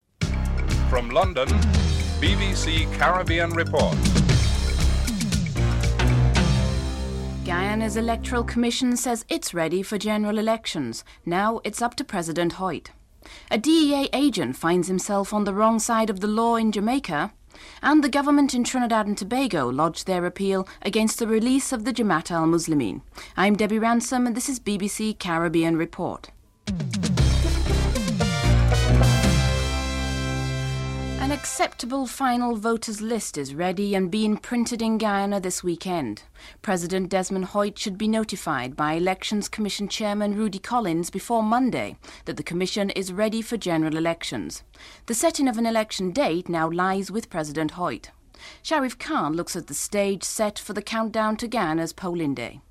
1. Headlines (00:00-00:30)
3. Peter Kostmayer, a US democratic congressman comments on the American government’s response to a call for an American observer team to monitor Guyana’s general elections (02:23-05:57)